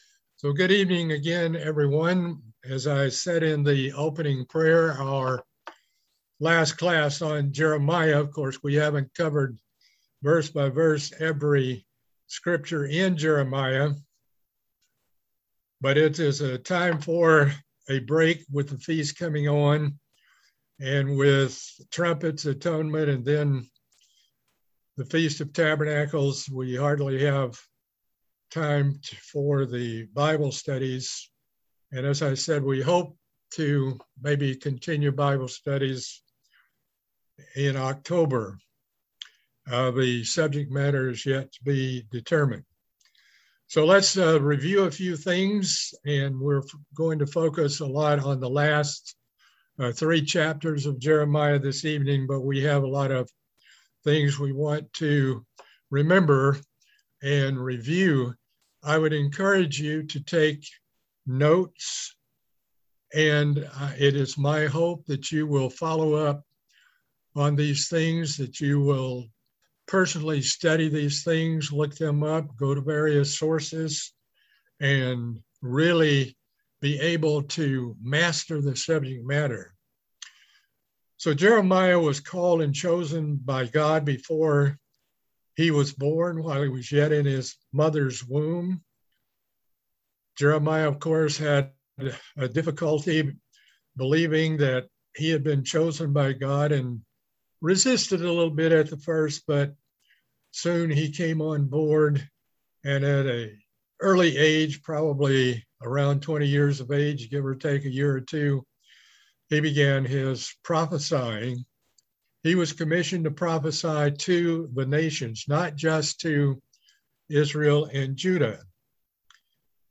Bible study series on the book of Jeremiah - Part 21